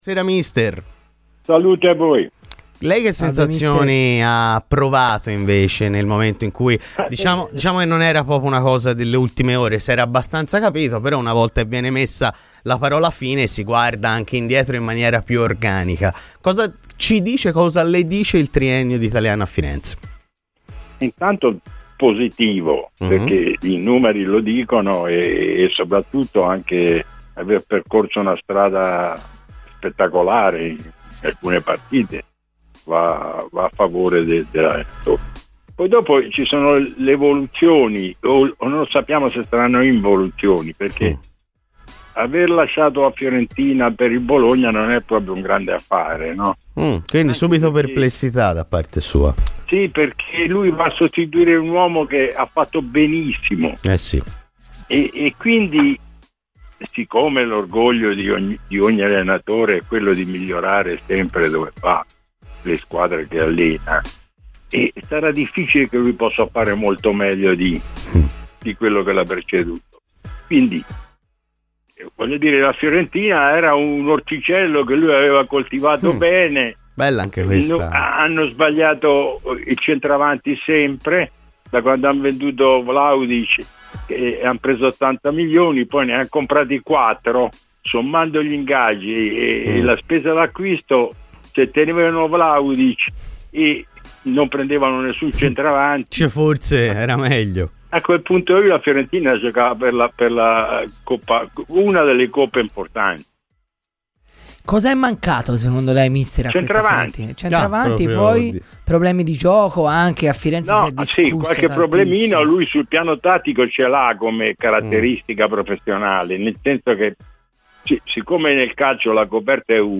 L'ex allenatore Corrado Orrico è intervenuto ai microfoni di Radio FirenzeViola nel corso della trasmissione "I Conti delle 7".